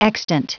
Prononciation du mot extant en anglais (fichier audio)
Prononciation du mot : extant